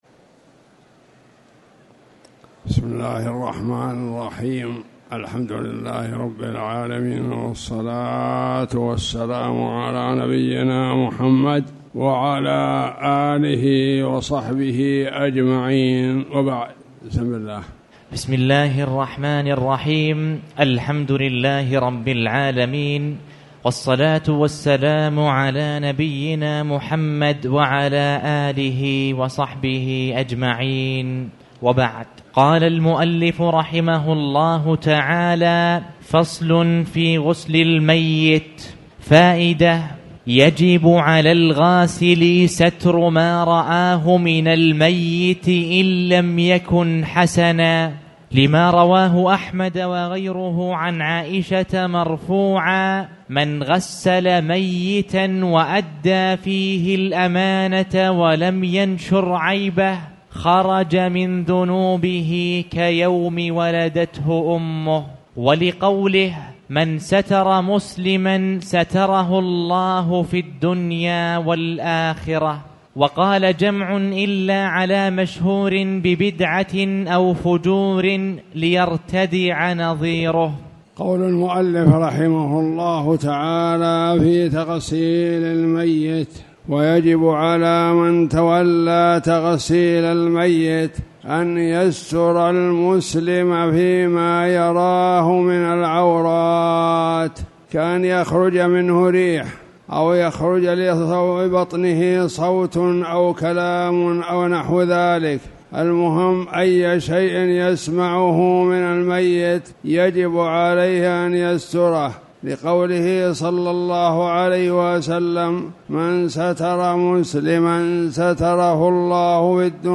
تاريخ النشر ١ صفر ١٤٣٩ هـ المكان: المسجد الحرام الشيخ